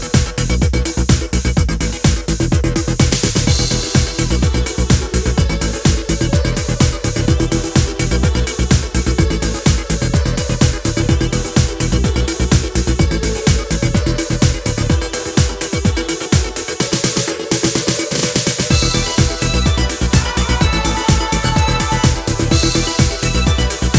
no Backing Vocals Dance 3:45 Buy £1.50